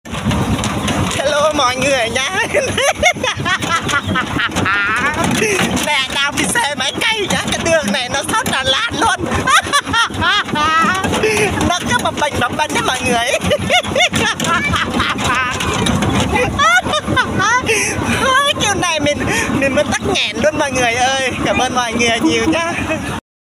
Thể loại: Câu nói Viral Việt Nam